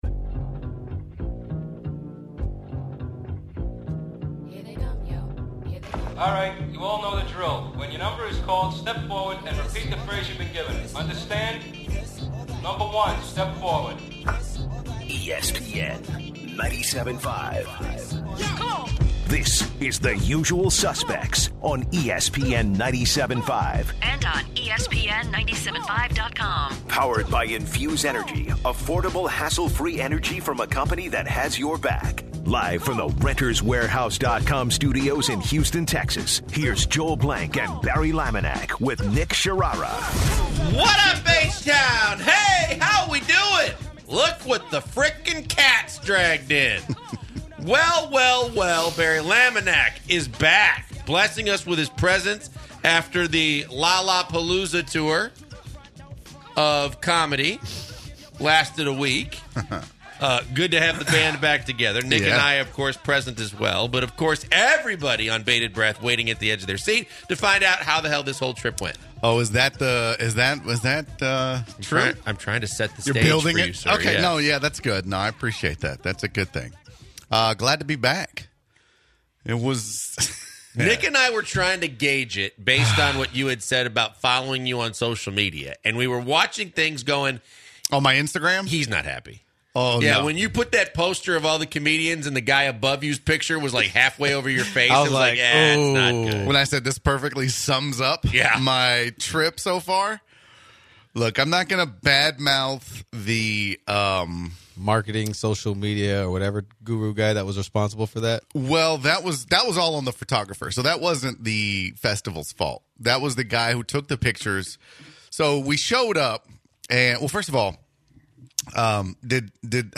finally back in the studio